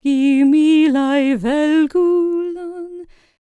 L CELTIC A11.wav